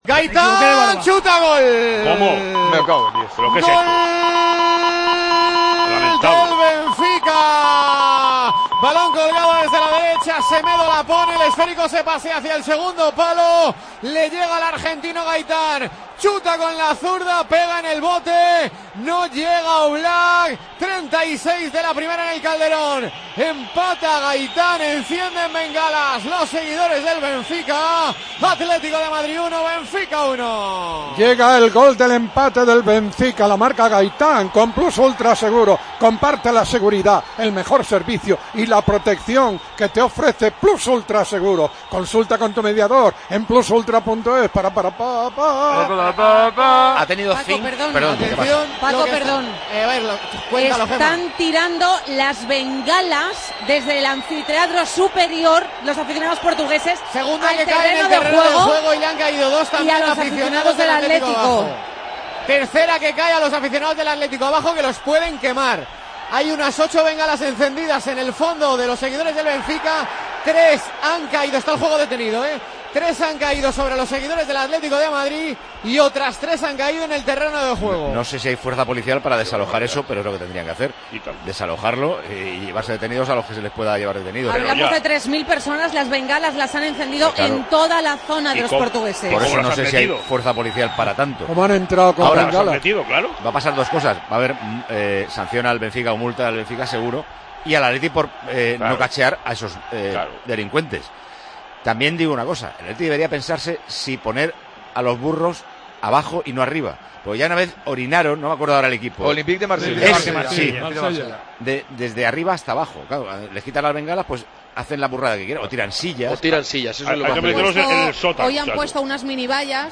Redacción digital Madrid - Publicado el 30 sep 2015, 22:22 - Actualizado 13 mar 2023, 23:03 1 min lectura Descargar Facebook Twitter Whatsapp Telegram Enviar por email Copiar enlace Gaitán remata solo desde la izquierda, al aprovechar un rechace de la defensa atlética. Celebración con bengalas por parte de la afición del equipo portugués.